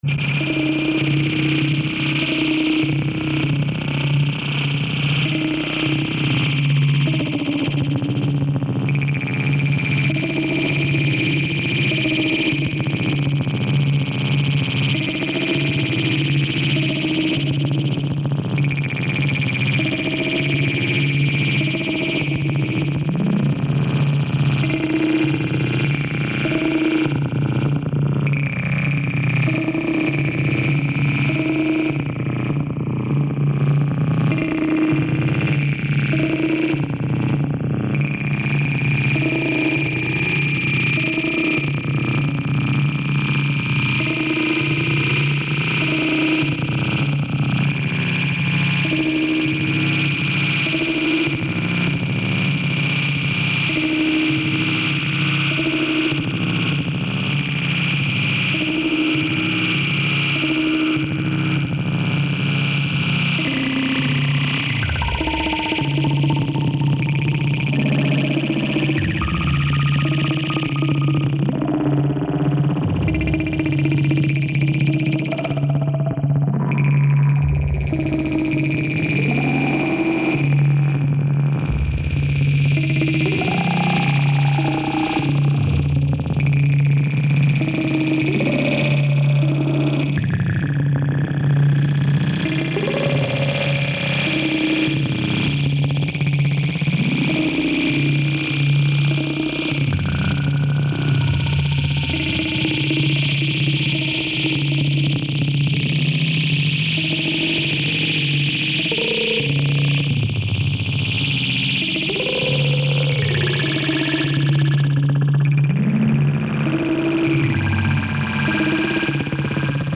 Tracks 6 & 7 recorded in 1982.